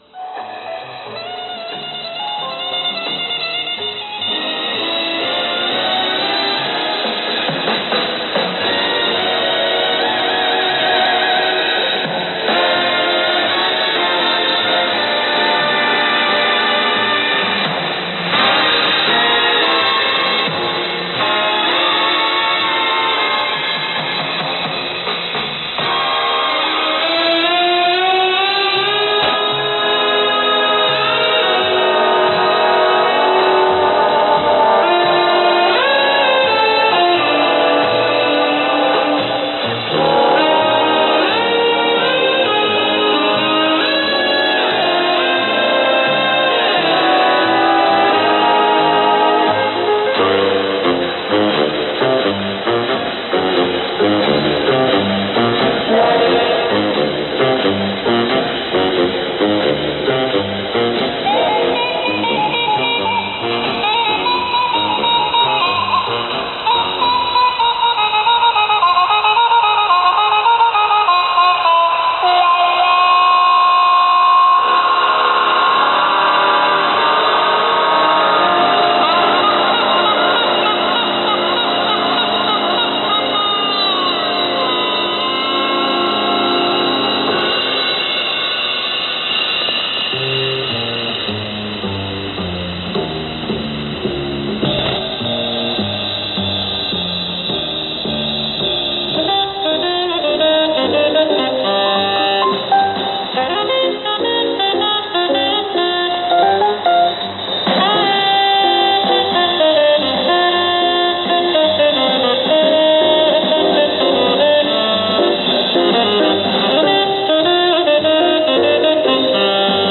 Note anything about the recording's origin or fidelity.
Sign-off at 0402.